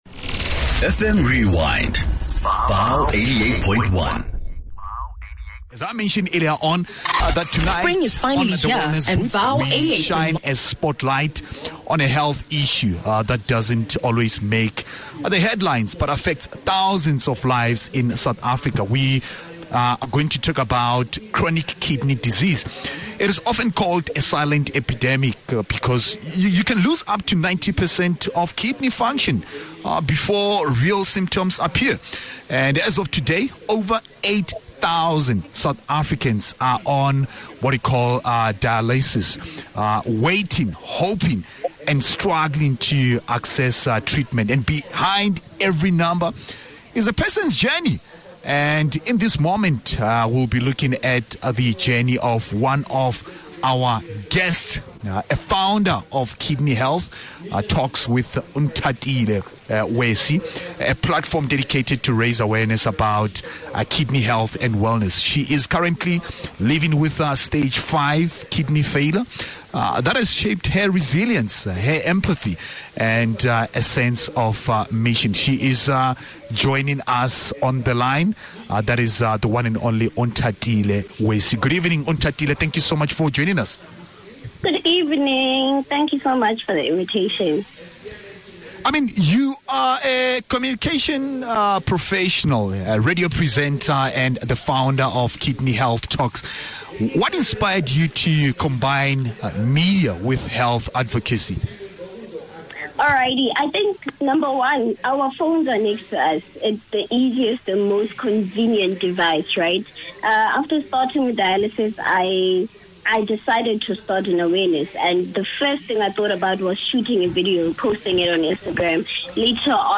In conversation with